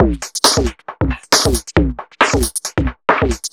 Index of /musicradar/uk-garage-samples/136bpm Lines n Loops/Beats
GA_BeatRingB136-02.wav